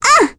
Requina-Vox_Damage_02.wav